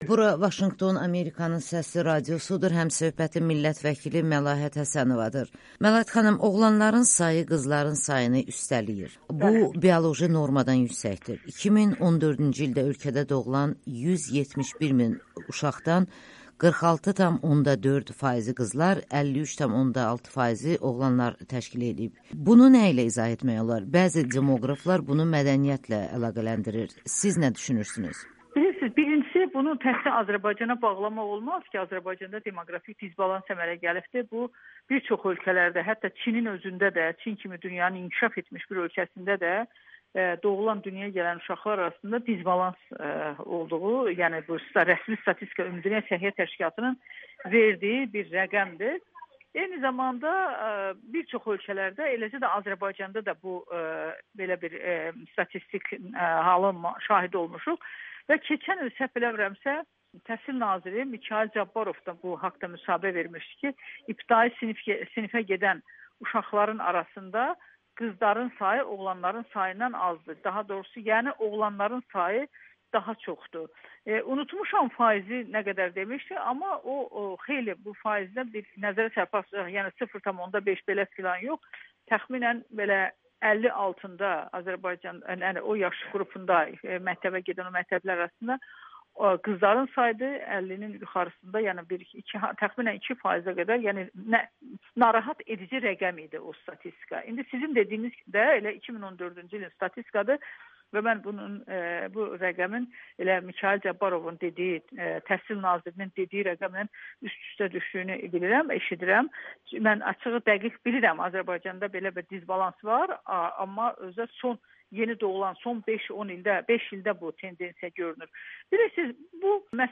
Məlahət Həsənova: Dünyaya gəlməmiş uşağın cinsinin müəyyən edilməsi inzibati xəta kimi qəbul olunmalıdır [Audio-Müsahibə]
Millət vəkili Amerikanın Səsi ilə söhbətdə Azərbaycanda doğulan uşaqların cins nisbəti barədə öz düşüncələrini paylaşıb